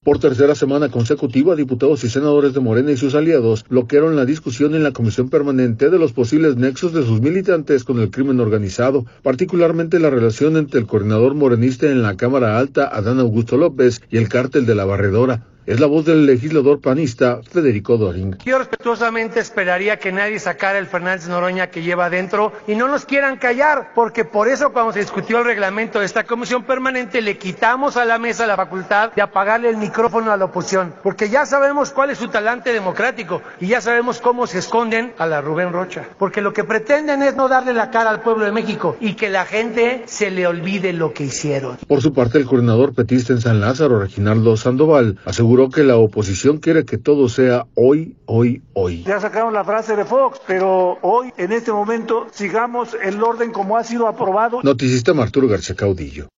audio Por tercera semana consecutiva diputados y senadores de Morena y sus aliados bloquearon la discusión en la Comisión Permanente, de los posibles nexos de sus militantes con el crimen organizado, particularmente la relación entre el coordinador morenista en la Cámara Alta, Adán Augusto López y el cartel de La Barredora. Es la voz del legislador panista Federico Döring.